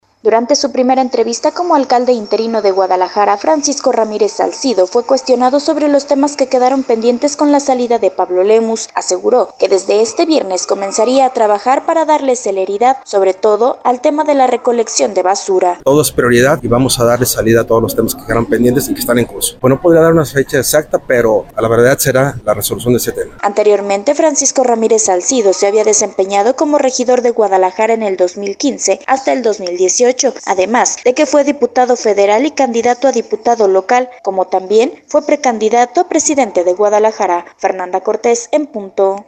Durante su primera entrevista como alcalde interino de Guadalajara, Francisco Ramírez Salcido fue cuestionado sobre los temas que quedaron pendientes con la salida de Pablo Lemus, él aseguró que desde este viernes comenzaría a trabajar para darles celeridad sobre todo en el tema de la recolección de la basura.